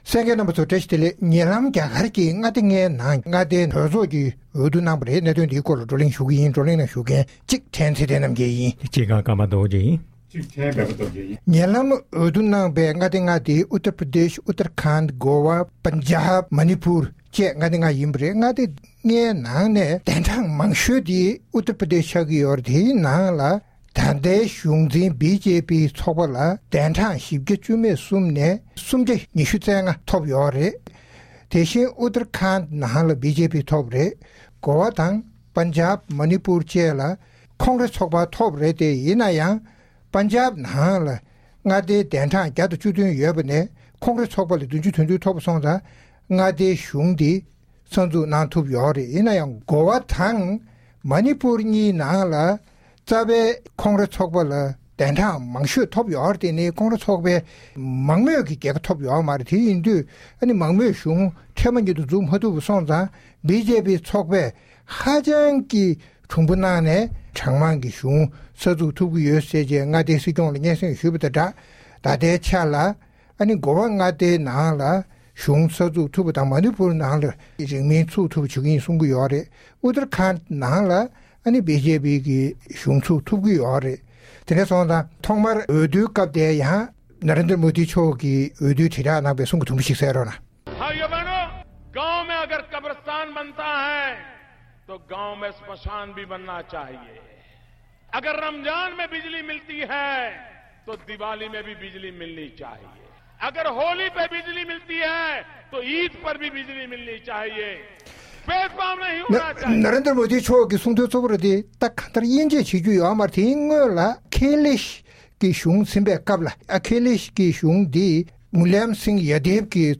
༄༅༎ཐེངས་འདིའི་རྩོམ་སྒྲིག་པའི་གླེང་སྟེགས་ཞེས་པའི་ལེ་ཚན་ནང་། ཉེ་ལམ་རྒྱ་གར་གྱི་མངའ་སྡེ་ལྔའི་ནང་མངའ་སྡེའི་གྲོས་ཚོགས་འཐུས་མིའི་འོས་བསྡུ་ལེགས་གྲུབ་བྱུང་ཞིང་། དབུས་གཞུང་འཛིན་ཚོགས་པ་BJP ལ་མངའ་སྡེ་ཆེ་ཤོས་Uttar Pradesh ནང་འཐུས་མིའི་གྲངས་༤༠༣ ནས་ ༣༢༥ འཐོབ་པ་སོགས་ཀྱི་སྐོར་རྩོམ་སྒྲིག་འགན་འཛིན་རྣམ་པས་བགྲོ་གླེང་གནང་བ་ཞིག་གསན་རོགས་གནང་།།